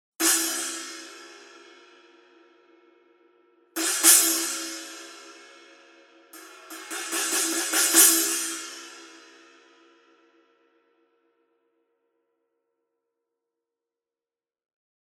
Тарелки серии Custom обладают широким частотным диапазоном, теплым плотным звуком и выдающейся музыкальностью.
Masterwork 14 Custom China sample
Custom-China-14.mp3